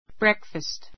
brékfəst ブ レ ク ふァ スト